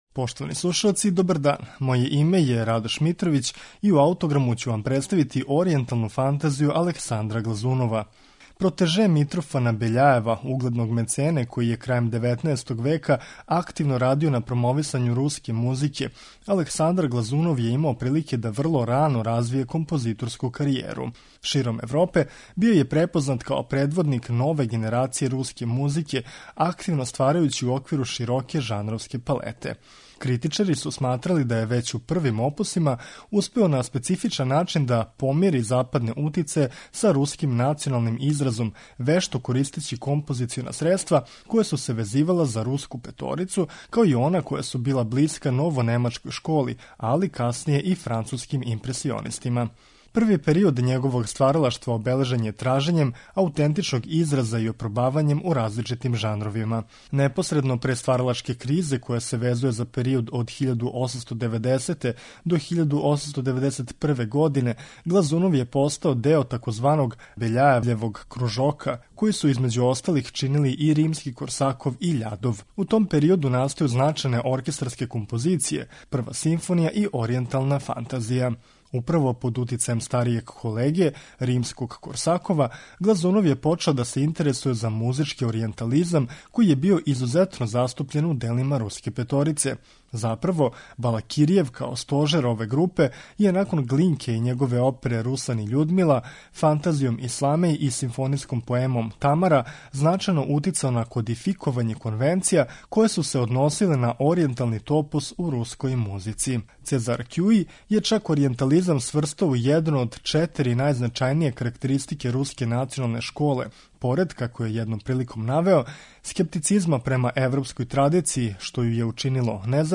Пре него што ће имати краткотрајну стваралачку кризу, Александар Глазунов ће 1889. написати једно опсежно симфонијско остварење - "Оријенталну рапсодију". У њој се чује младалачки језик Глазунова и утицај оријентализма Руске националне школе. Ово дело ћемо слушати у извођењу Московског државног оркестра, под управом Веронике Дударове.